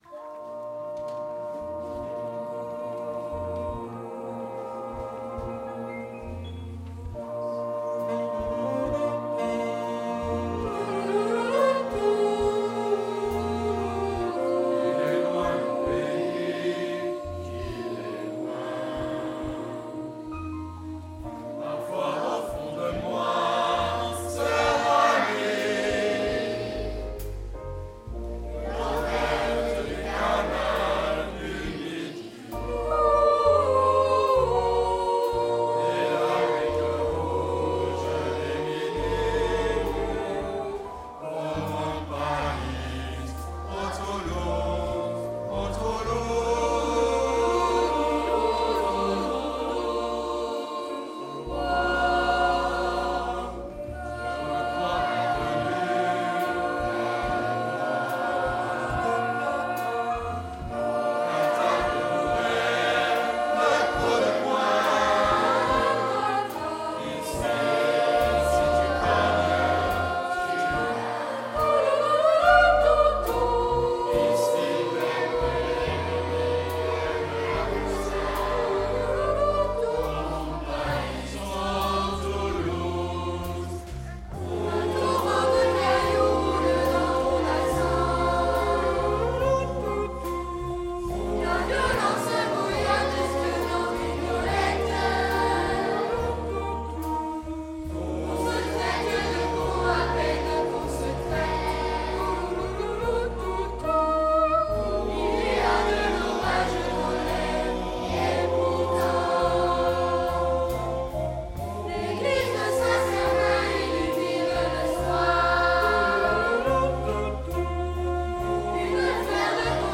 Concert basé sur les chansons toulousaines, en collaboration avec la maîtrise de la Cathédrale Saint-Etienne et l’école Saint-Joseph.